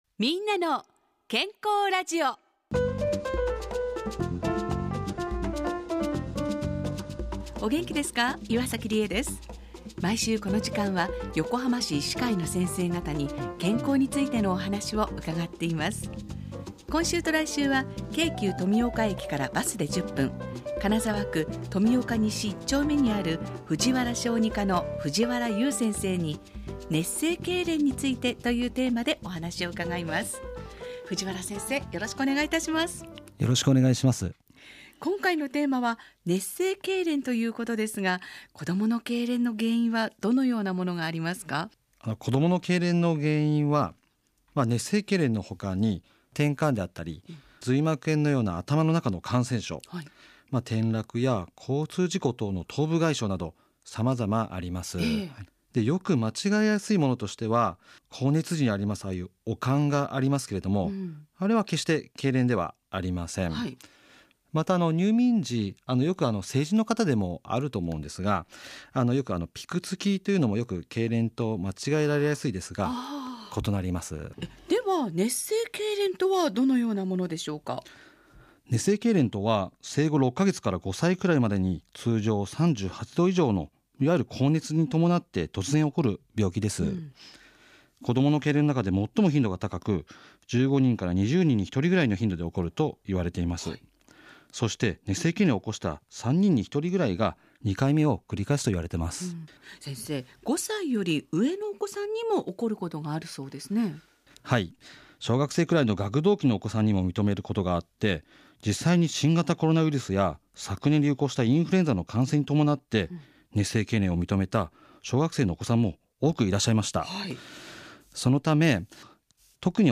ラジオ番組 みんなの健康ラジオ